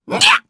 Lakrak-Vox_Damage_jp_01_b.wav